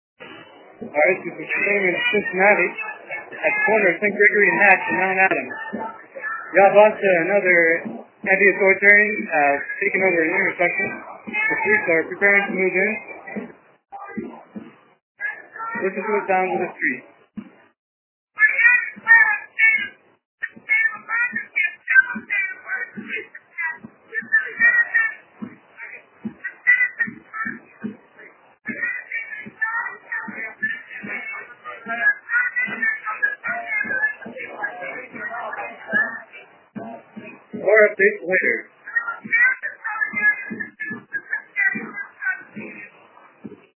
Cincinnati anti-authoritarian bloc protesters take over an intersection in Cinci and speak out as police move in. (0:43)